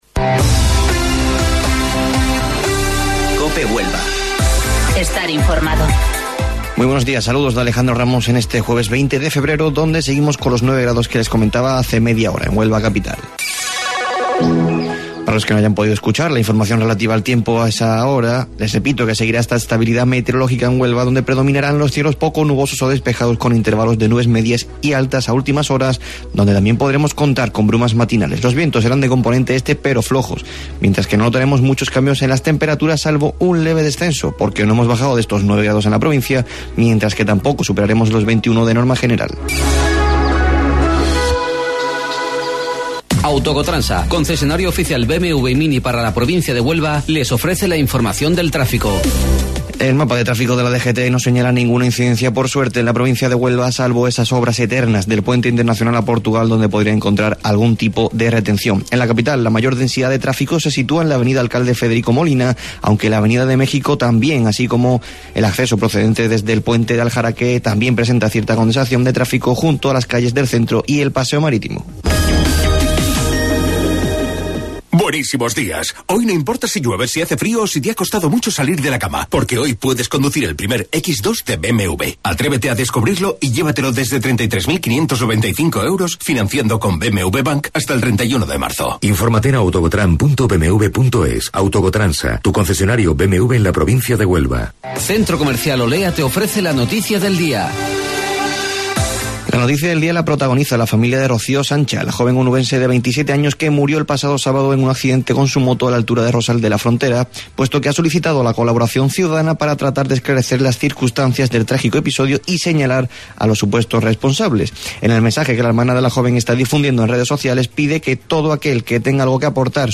AUDIO: Informativo Local 08:25 del 20 Febrero